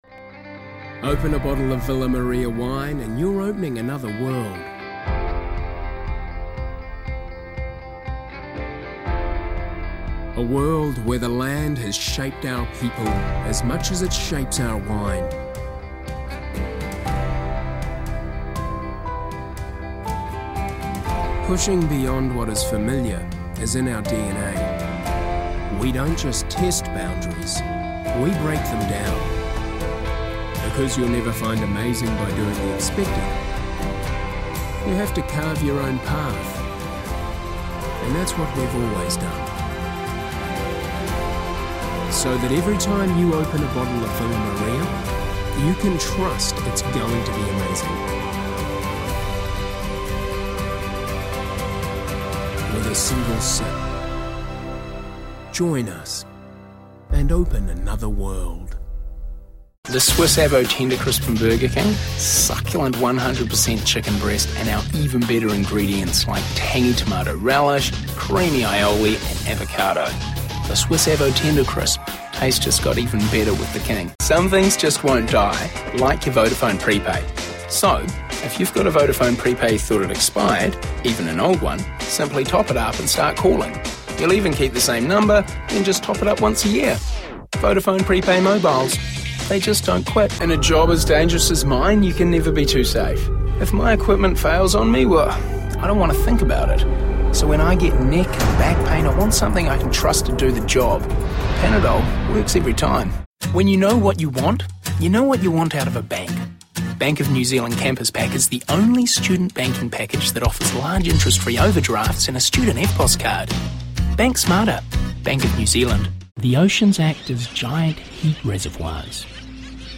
Demo
Adult
new zealand | natural
ANIMATION 🎬